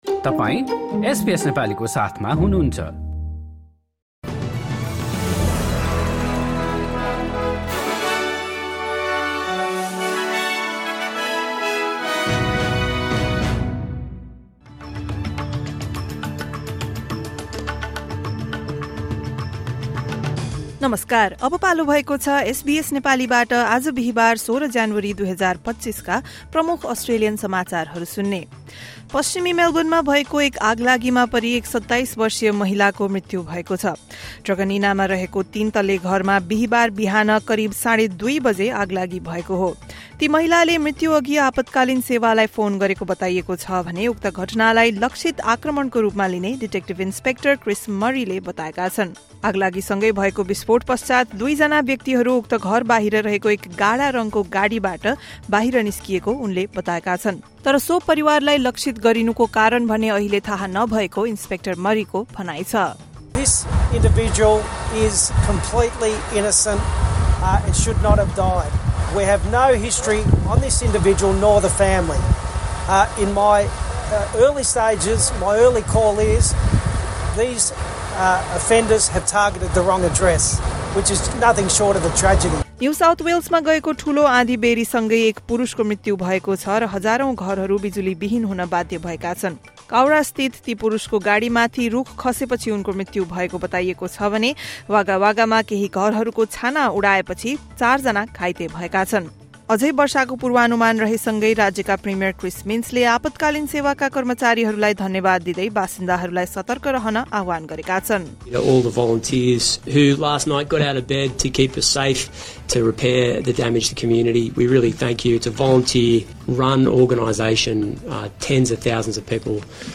SBS Nepali Australian News Headlines: Thursday, 16 January 2025